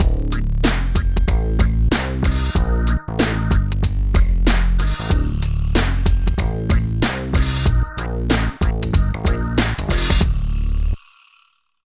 87 LOOP   -L.wav